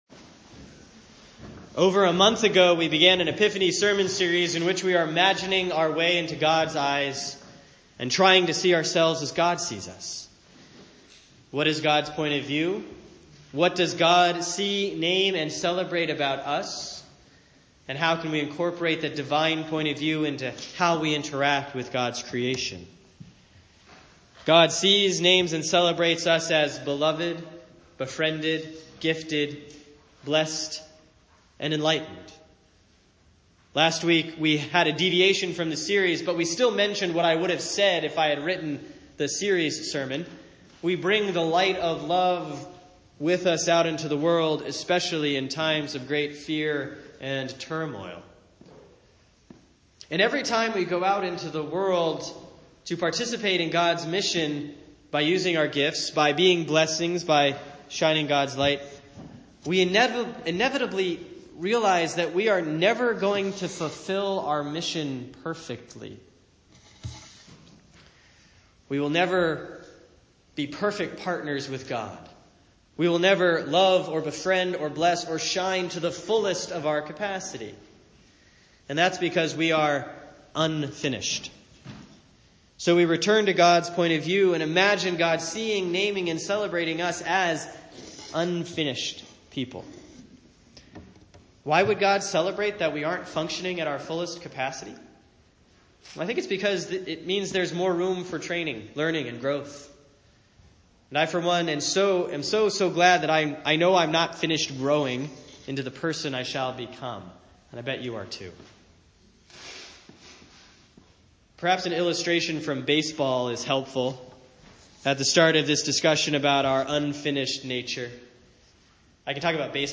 Sermon for Sunday, February 12, 2017